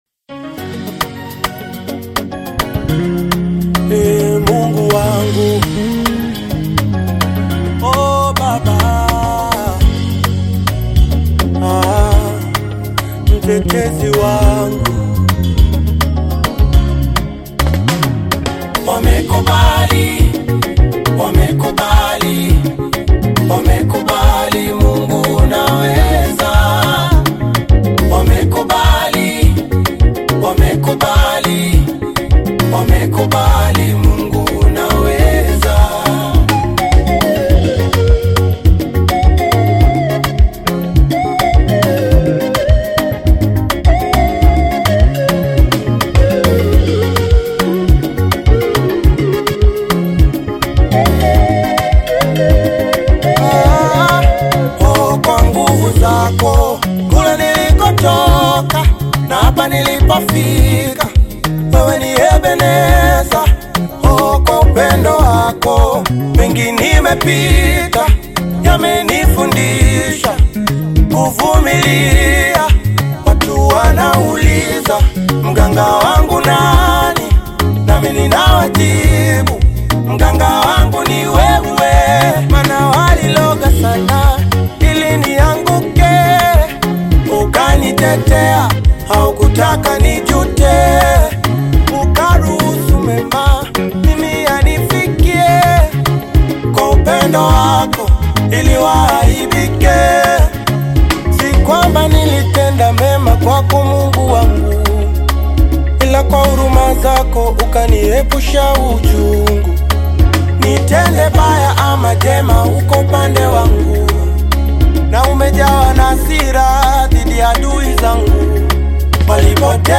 Tanzanian Gospel singer and songwriter
gospel song
African Music